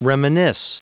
Prononciation du mot reminisce en anglais (fichier audio)
Prononciation du mot : reminisce